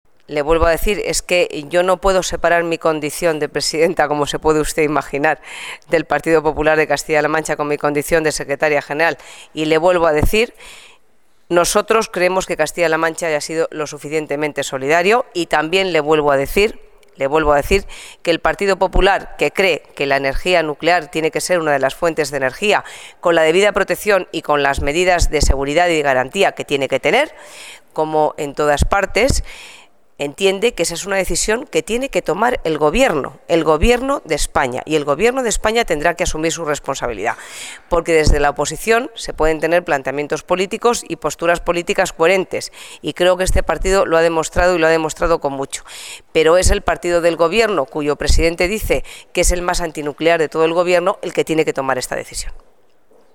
DECLARACIONES DE COSPEDAL SOBRE EL ATC (Tamaño: 833,4 kb.)
María Dolores de Cospedal, secretaria general del Partido Popular, resumía a mediodía del miércoles su posición sobre la instalación de un ATC en Guadalajara, a preguntas de este diario, y lo hacía de forma clara: "Castilla-La Mancha es una región muy solidaria y hemos cumplido la cuota de solidaridad" en materia de energía nuclear.